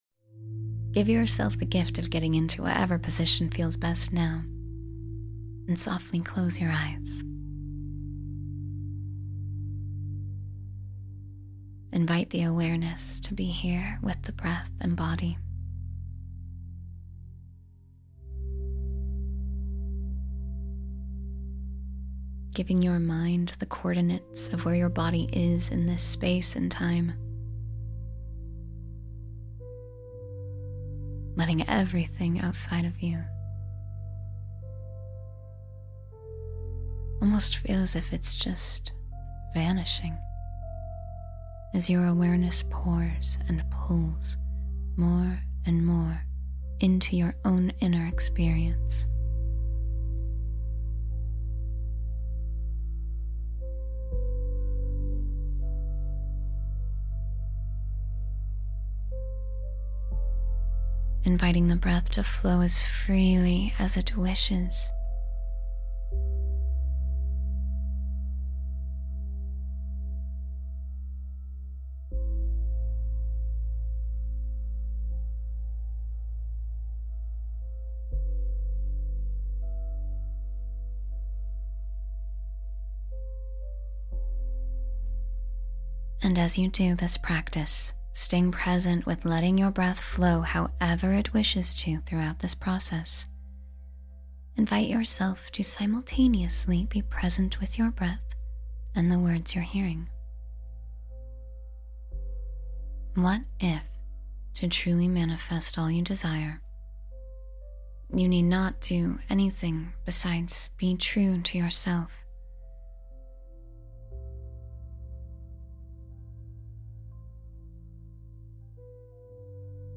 Manifest Dreams Through Guided Abundance — Meditation for Prosperity